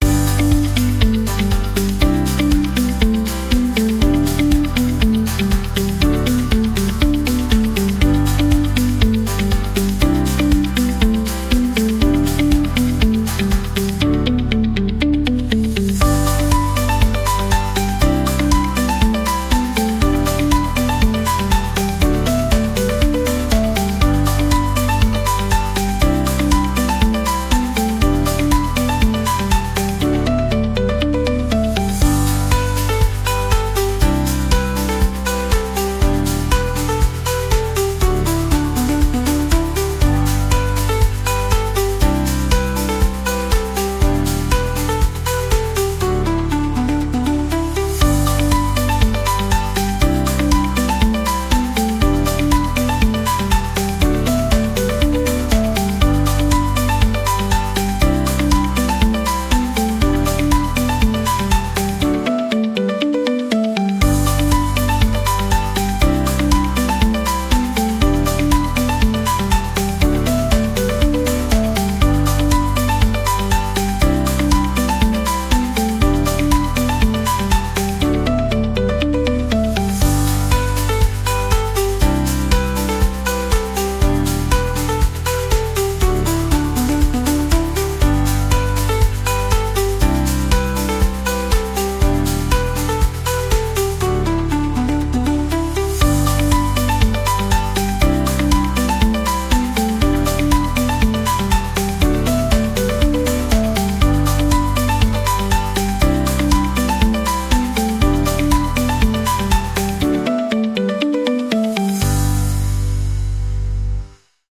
• Качество: Хорошее
• Категория: Детские песни / Музыка детям 🎵